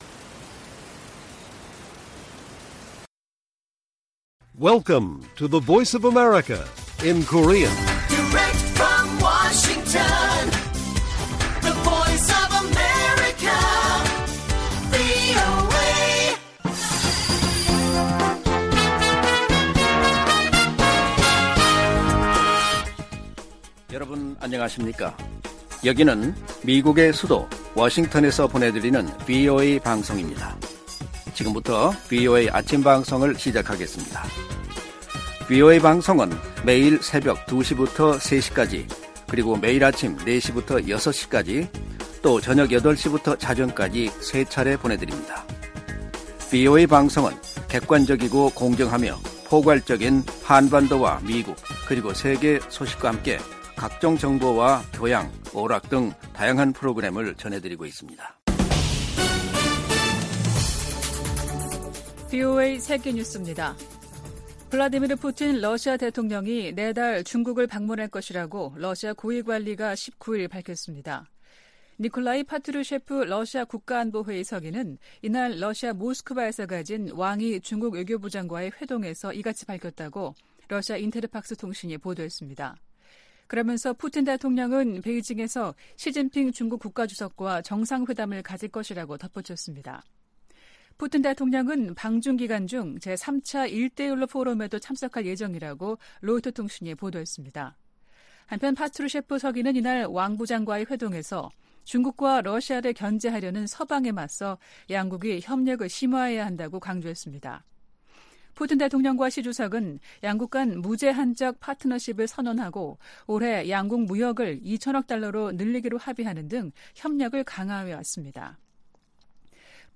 세계 뉴스와 함께 미국의 모든 것을 소개하는 '생방송 여기는 워싱턴입니다', 2023년 9월 20일 아침 방송입니다. '지구촌 오늘'에서는 제78차 유엔총회 고위급 일반토의가 19일 개막한 가운데, 안전보장이사회 상임이사국 중 미국을 제외한 4개국 정상이 불참하는 소식 전해드리고, '아메리카 나우'에서는 도널드 트럼프 전 대통령이 공화당 2차 토론에도 안 나간다는 이야기 살펴보겠습니다.